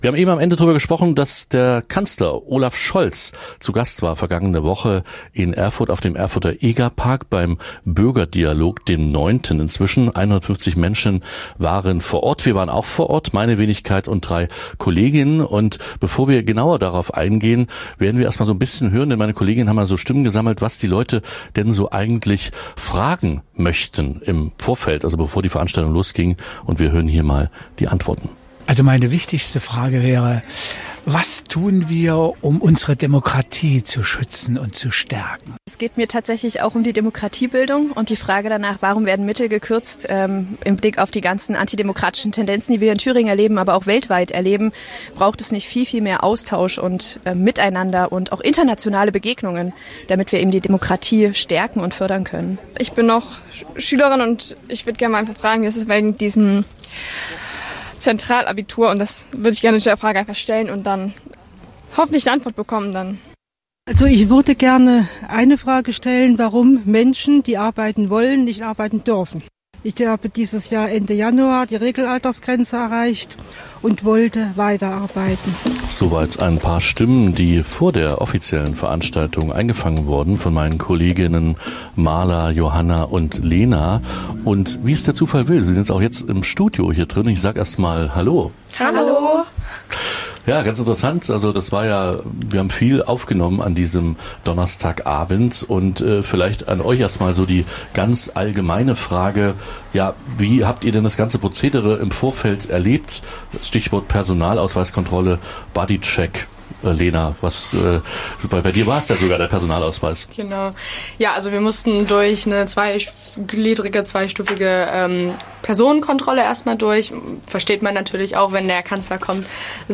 Kolleginnen-Gespräch über den Besuch des Kanzlers in Erfurt